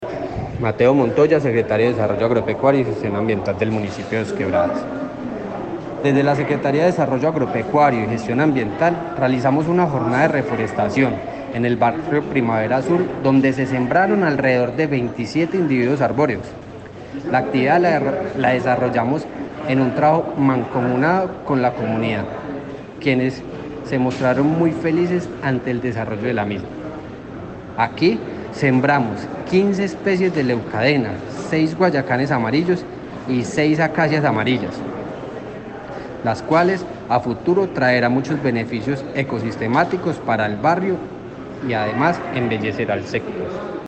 Comunicado-249-Audio-1-Secretario-de-Desarrollo-Agropecuario-y-Ambiental-Mateo-Montoya.mp3